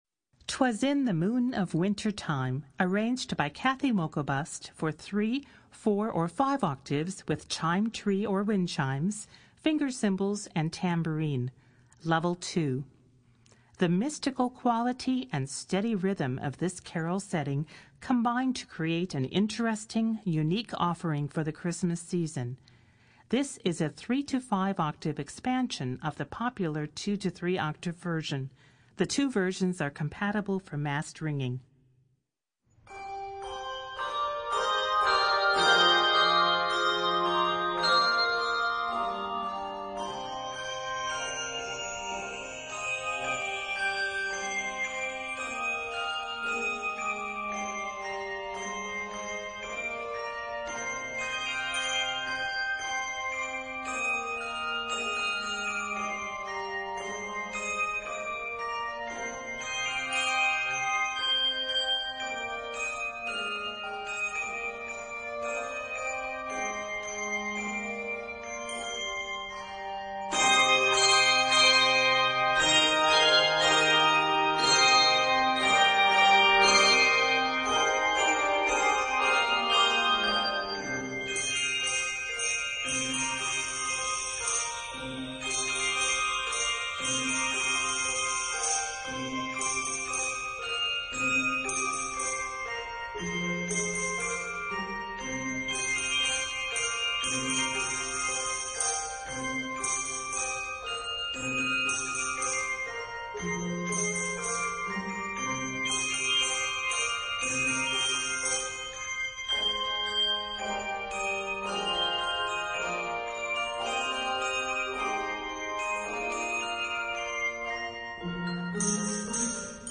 Voicing: Handbells 3-5 Octave